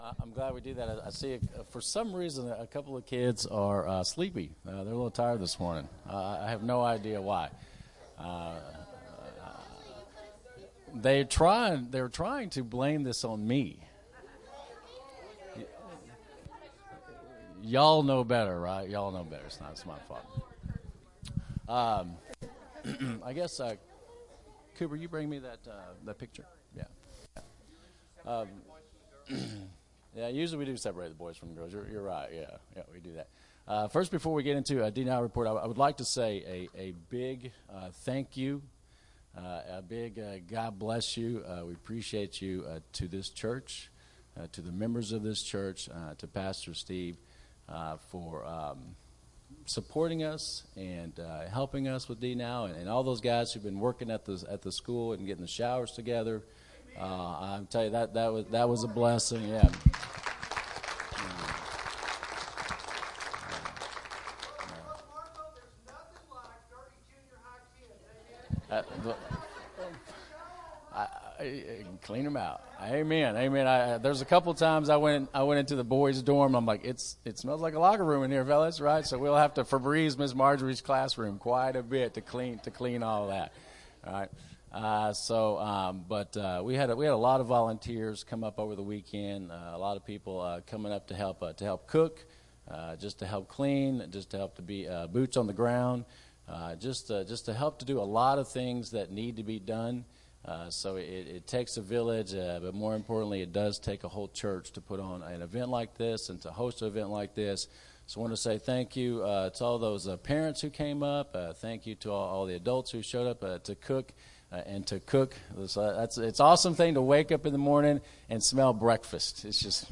Bible Text: John 14:6, Psalm 1:1-6 | Preacher